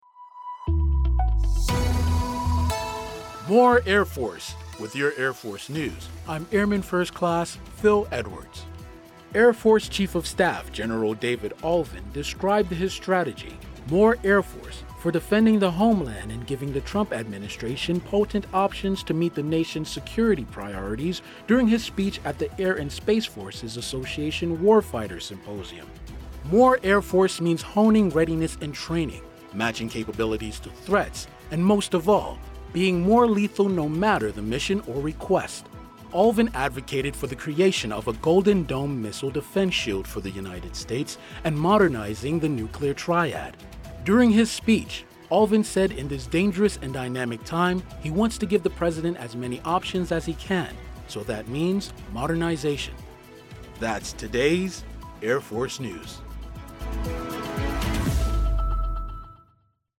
Features audio news stories involving U.S. Air Force technology, personnel, and operations around the globe.